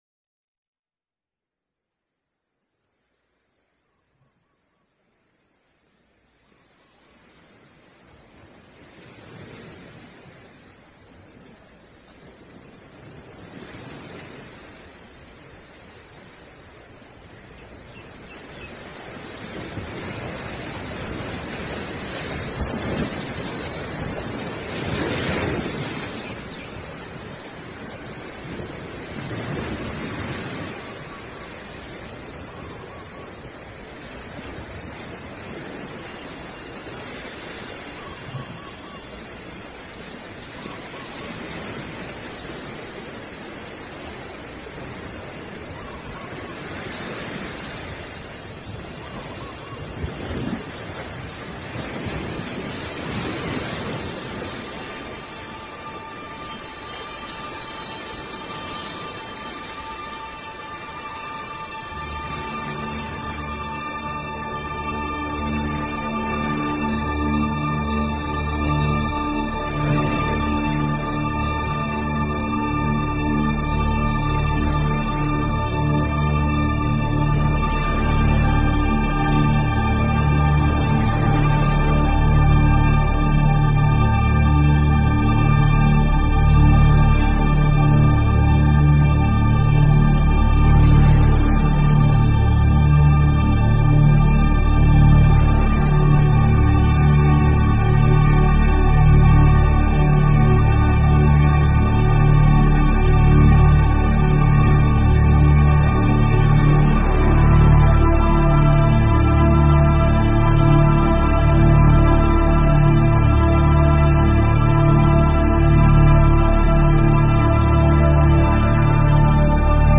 The show is a sound combination of discussion and experience including the following topics: